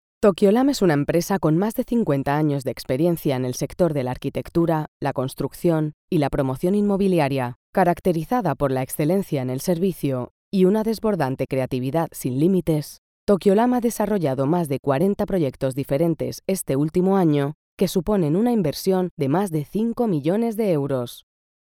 European Spanish female voice over talent with Castilian accent.
kastilisch
Sprechprobe: Industrie (Muttersprache):